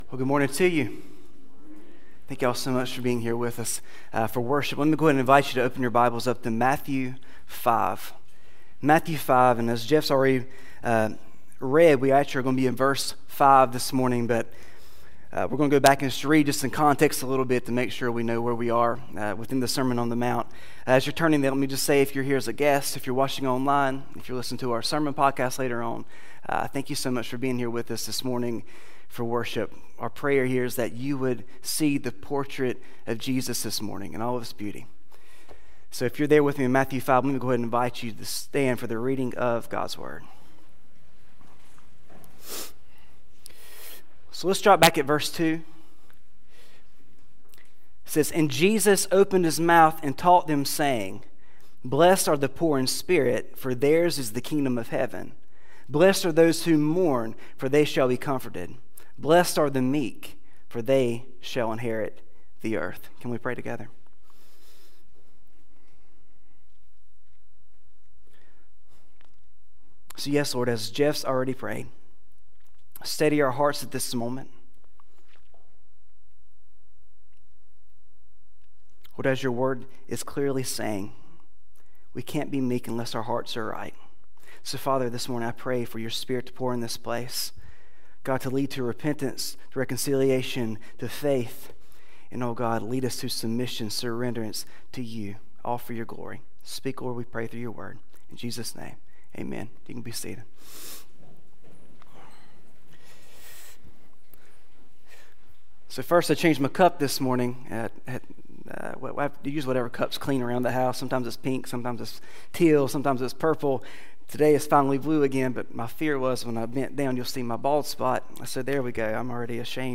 Battleground Community Church Sermons